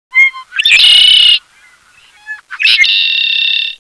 Black Bird Sounds
black-bird-soudns.wav